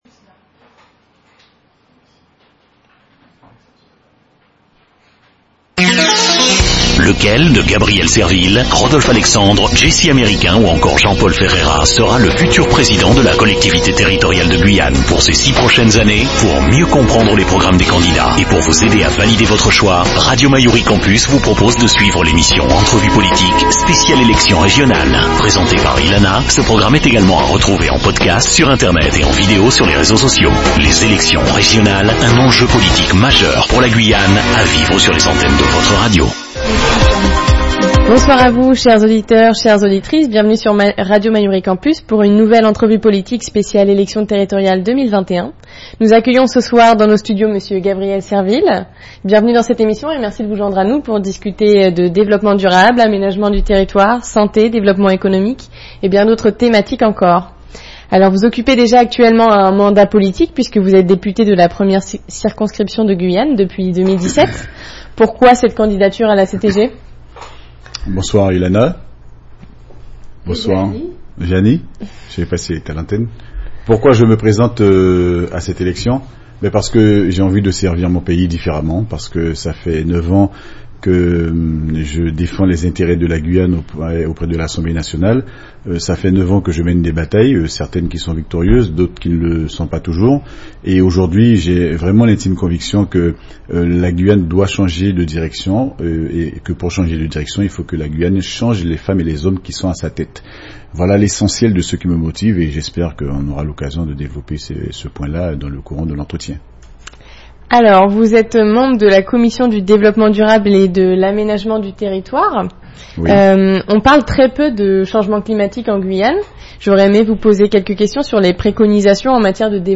dans Entrevue politique